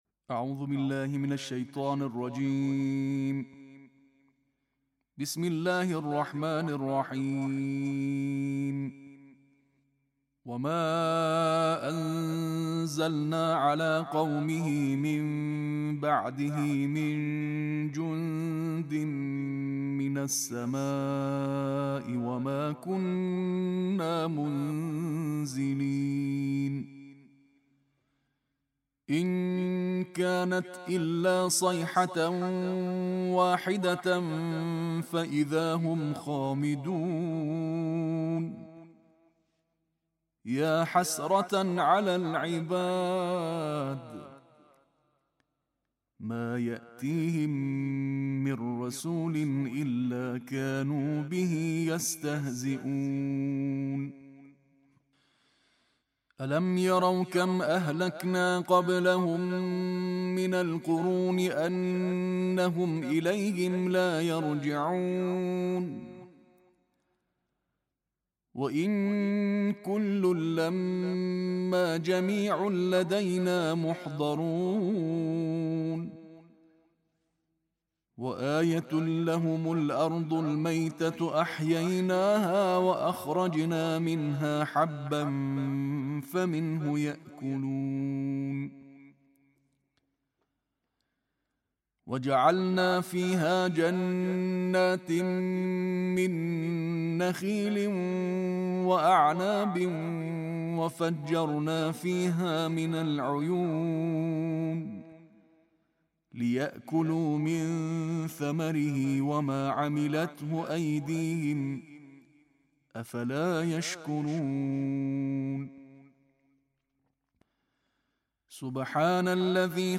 Tarteel / 23
Tags: coran ، tarteel ، lecture en tarteel ، ramadan